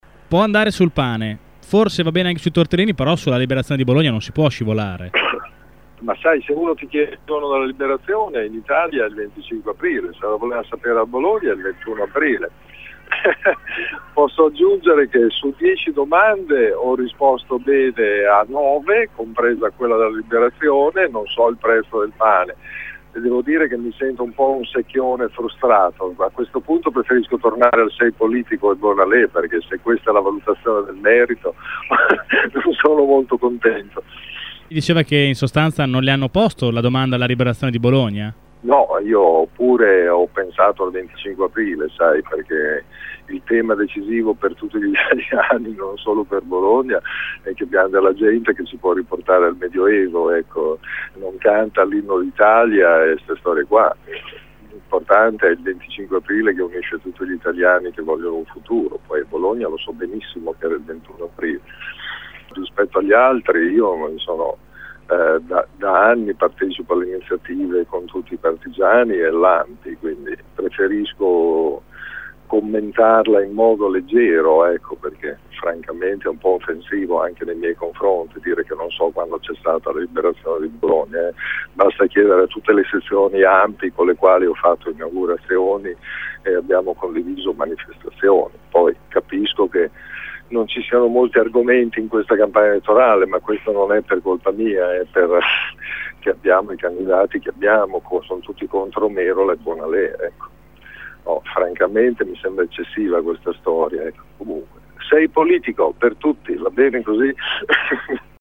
Ai nostri microfoni afferma di non sentirsi il primo della classe, non è questo il modo per giudicare  i candidati, e verso i suoi avversari “tutti coalizzati contro Merola” è generoso: “do il sei politico a tutti“.